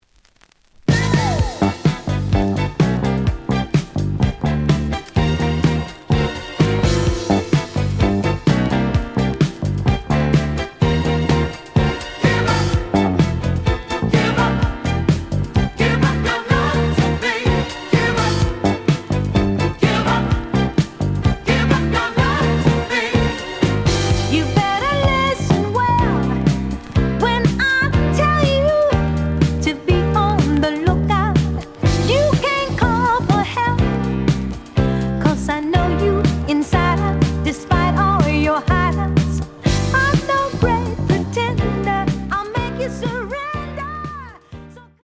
The audio sample is recorded from the actual item.
●Genre: Disco
B side plays good.)